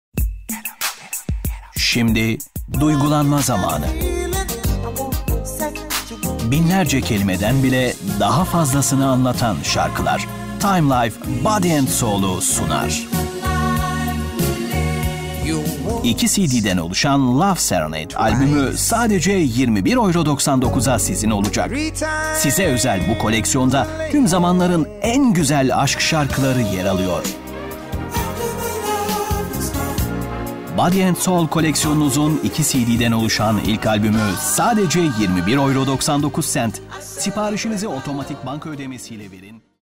Sprecher türkisch.
Sprechprobe: Sonstiges (Muttersprache):